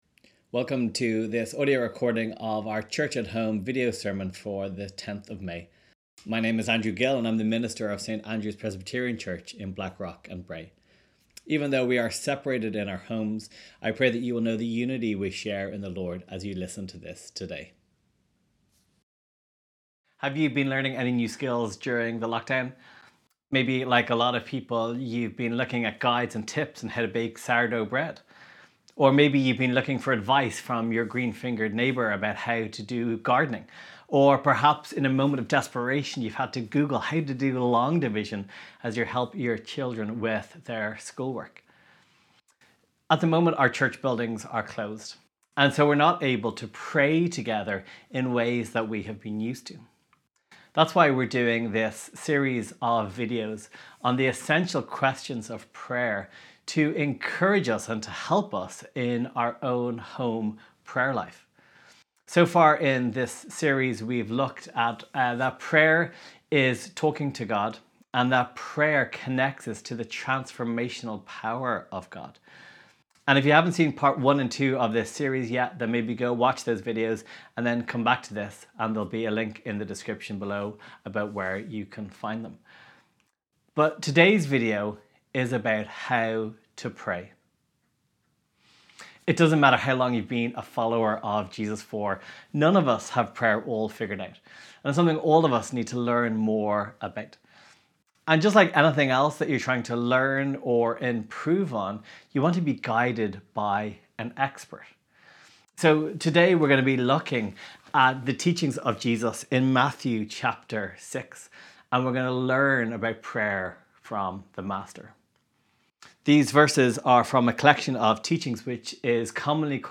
Sermon Library: Prayer Questions